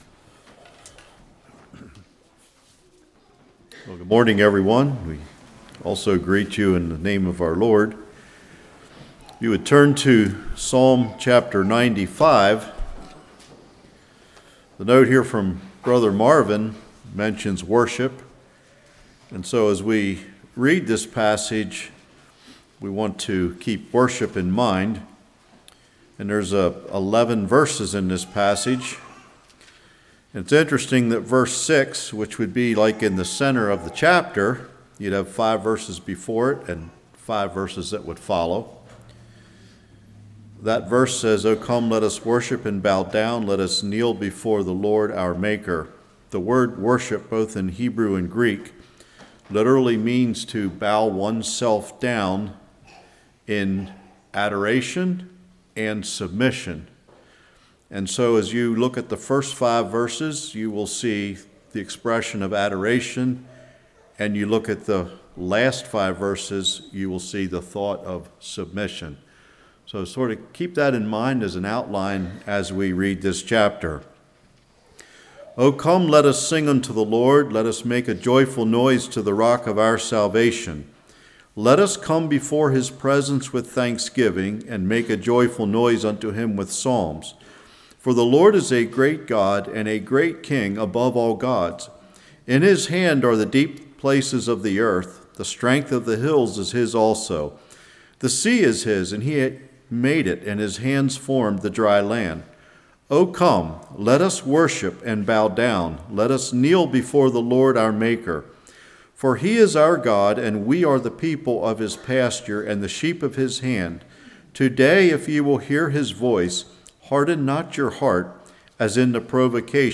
Psalms 95:1-11 Service Type: Morning Key Verse